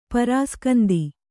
♪ prāskanda